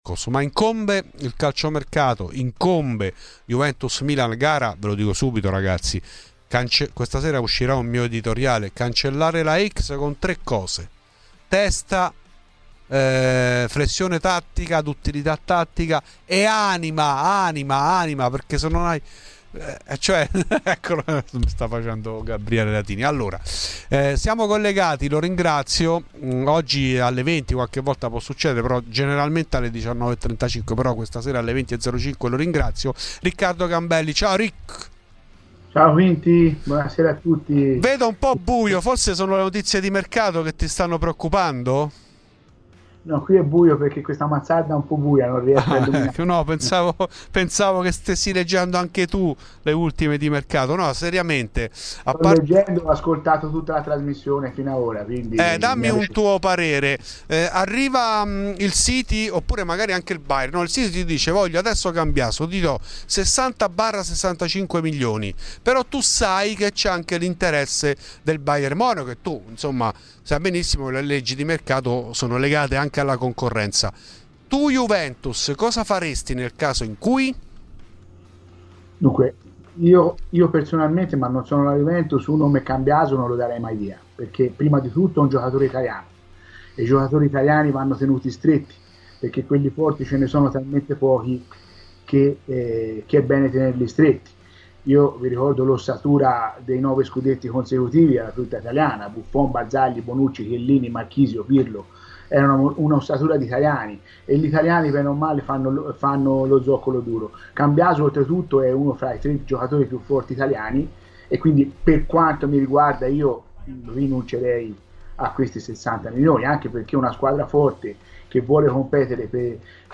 ha parlato a Fuori di Juve, trasmissione di Radio Bianconera.